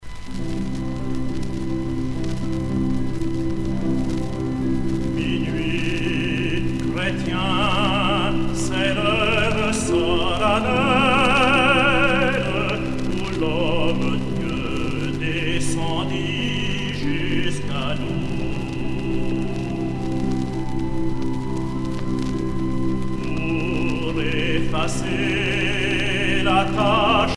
Noël